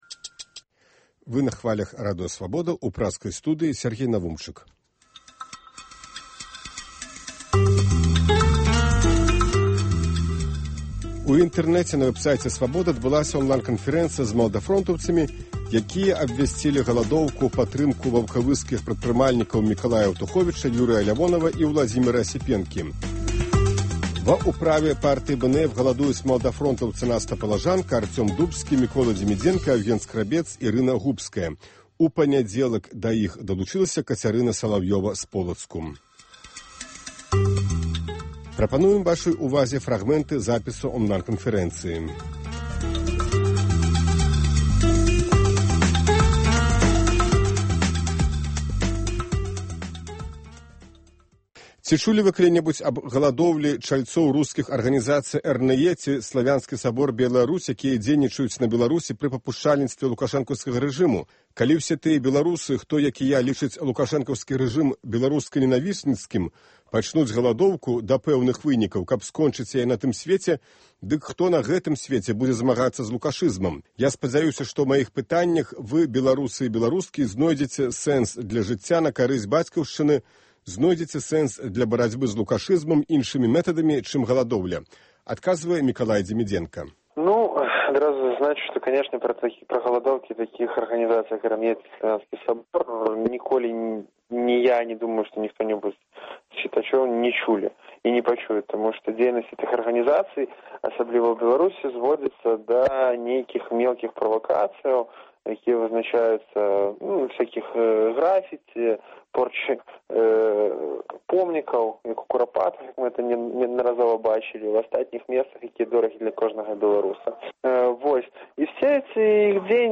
Онлайн- канфэрэнцыя
Онлайн-канфэрэнцыя з маладафронтаўцамі, якія абвясьцілі галадоўку ў падтрымку арыштаваных ваўкавыскіх прадпрымальнікаў.